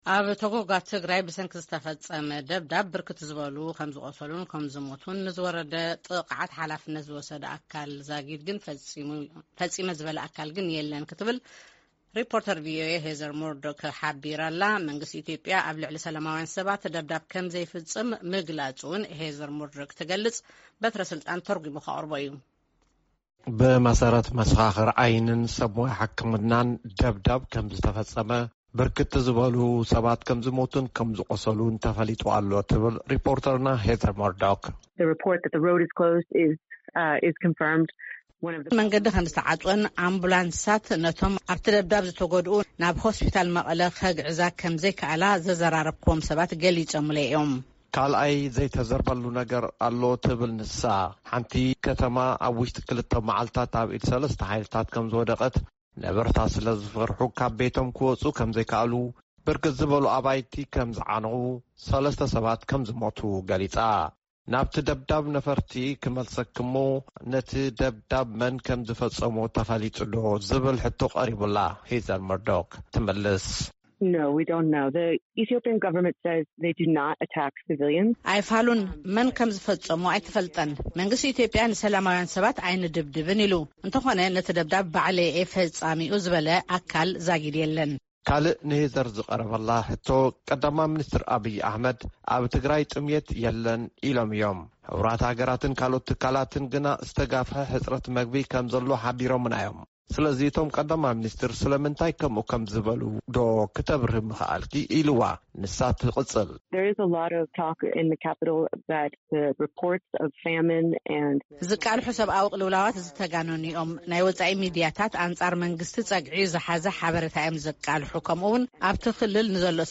ቃለ መሕትት ምስ ናብ ኢትዮጵያ ዝበጽሓት ሪፖርተር ድምጺ ኣሜሪካ